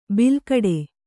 ♪ bilkaḍe